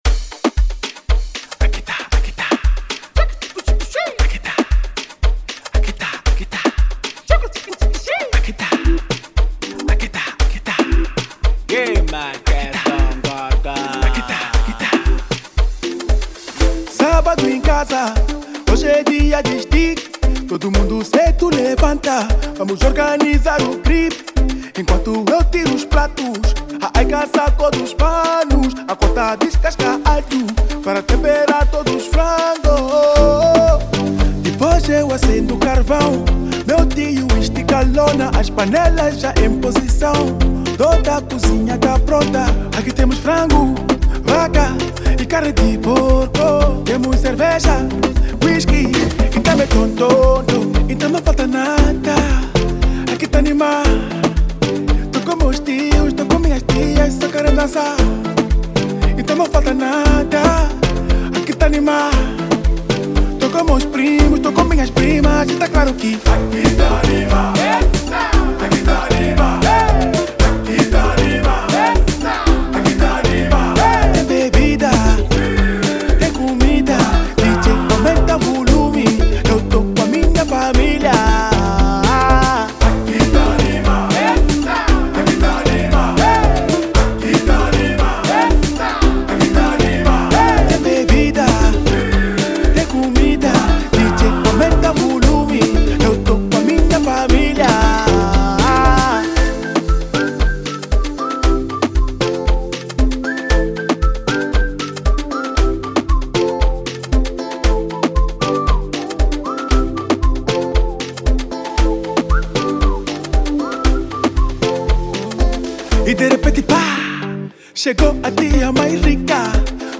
Genero: Afrobeat